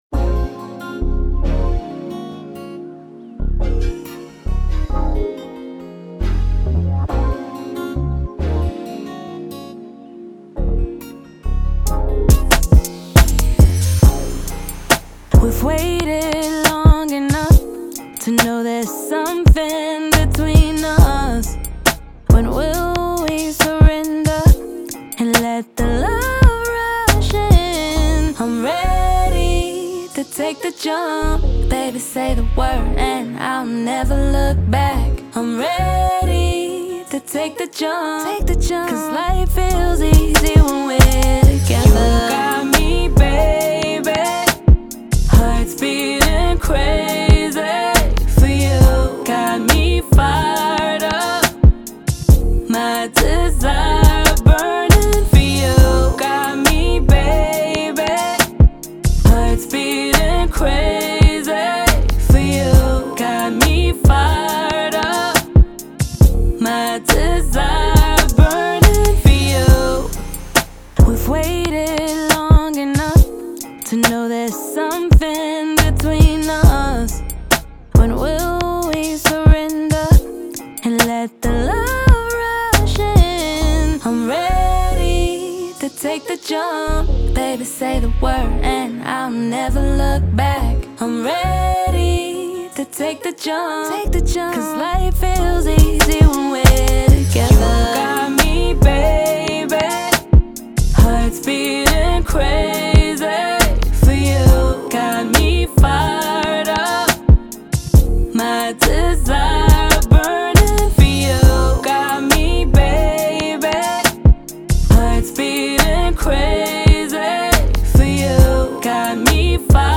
R&B, Soul, Hip Hop
Bb min